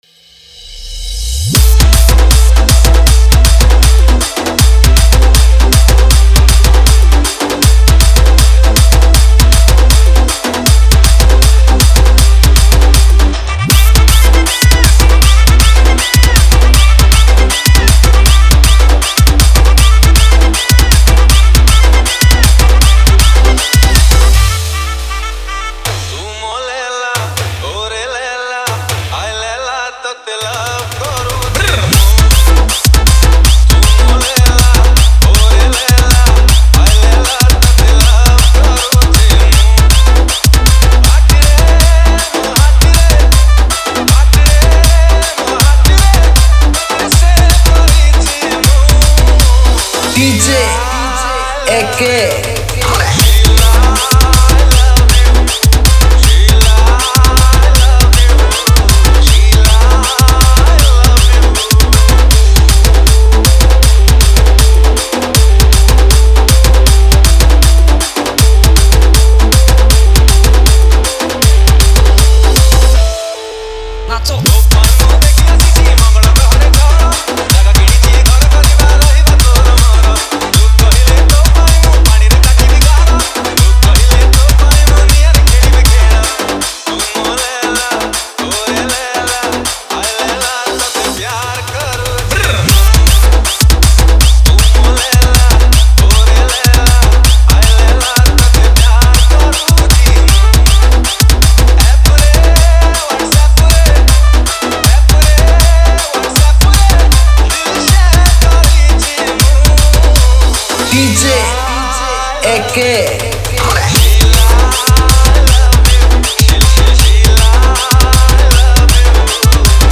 Tapori Dance Mix